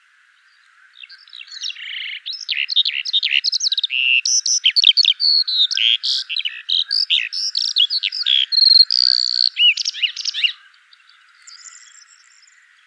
Alauda arvensis - Skylark - Allodola